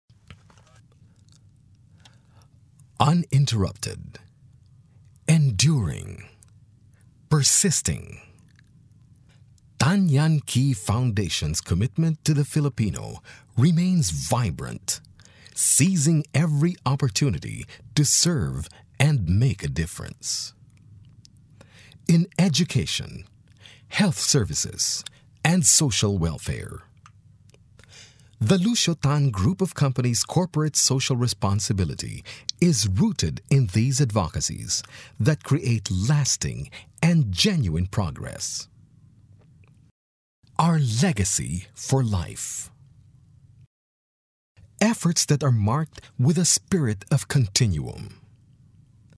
englisch (us)
Sprechprobe: Industrie (Muttersprache):